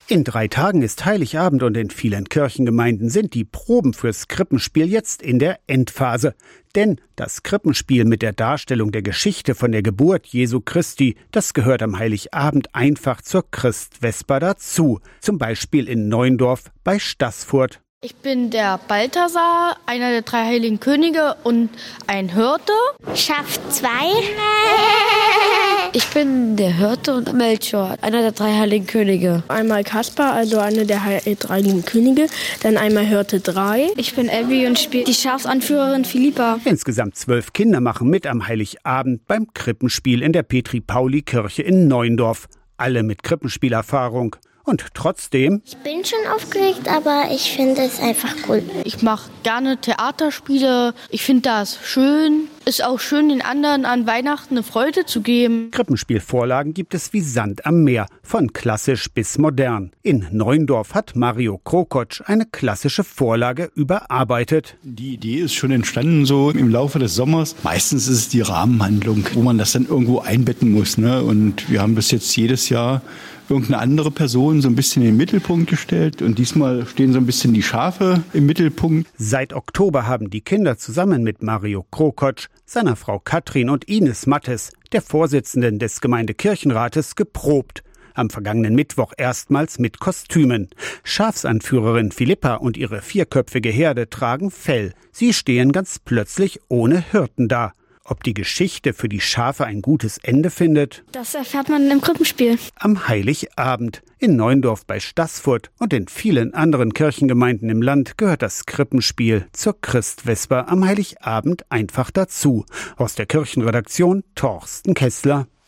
Krippenspielprobe in Neundorf bei Staßfurt
iad-radio-saw-krippenspielprobe-in-neundorf-bei-stassfurt-44296.mp3